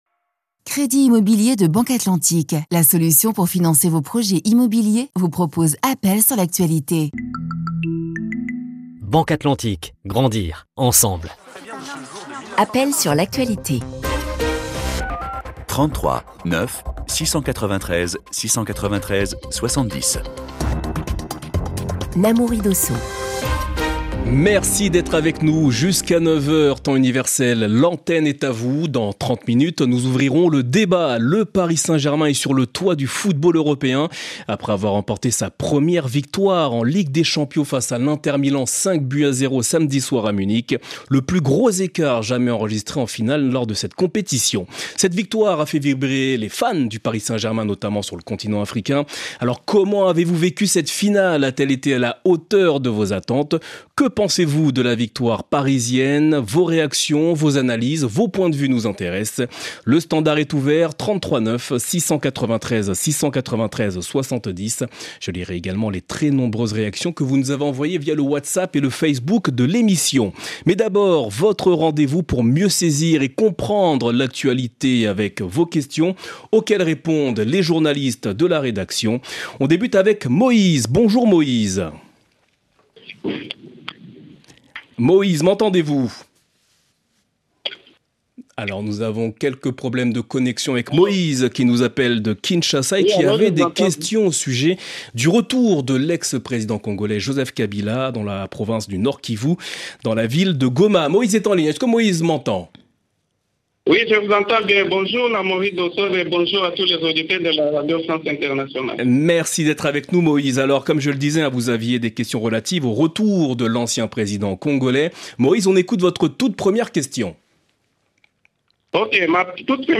1 [Vos questions] Mali : le FLA peut-il défier l’armée malienne et Africa Corps ? 19:30 Play Pause 10h ago 19:30 Play Pause Відтворити пізніше Відтворити пізніше Списки Подобається Подобається 19:30 Les journalistes et experts de RFI répondent également à vos questions sur les accusations de détournement de fonds à l'encontre du ministre de la Justice et le conflit qui perdure entre Israël et l'Iran.